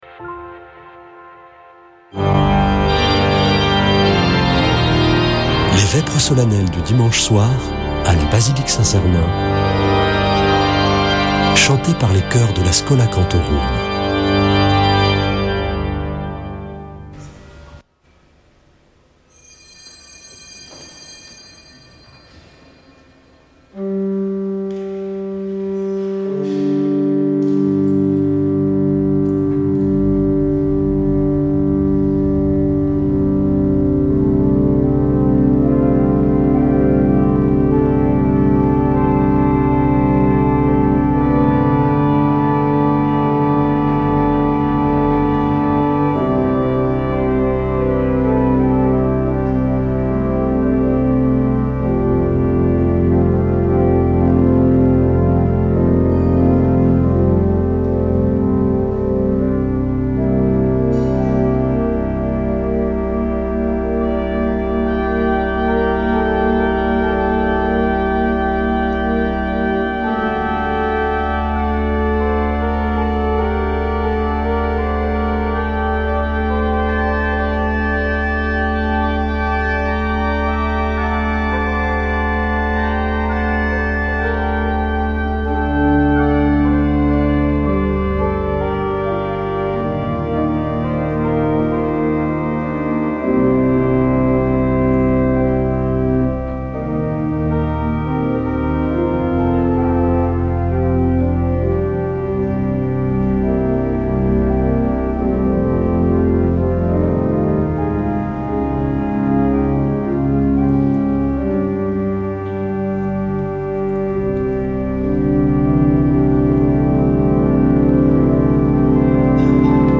Vêpres de Saint Sernin du 18 mai
Une émission présentée par Schola Saint Sernin Chanteurs